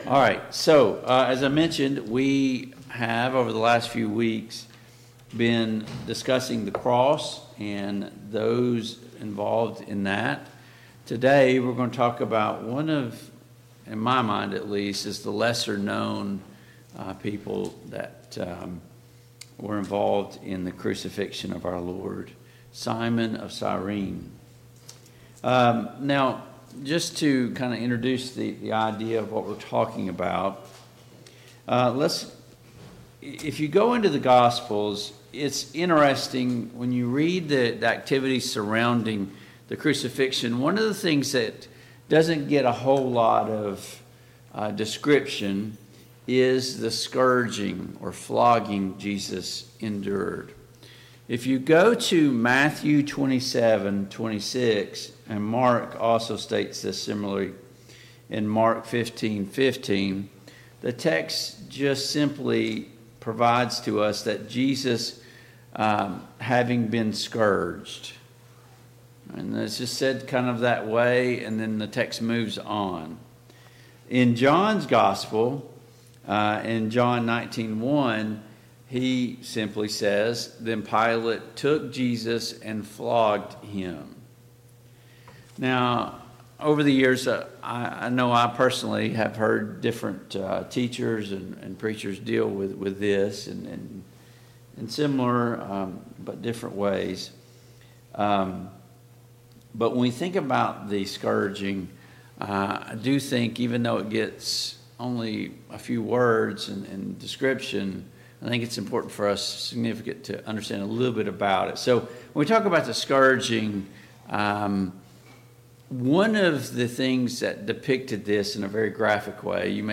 The Cast of the Cross Service Type: Sunday Morning Bible Class Topics: Bearing your Cross , Simon of Cyrene « 7.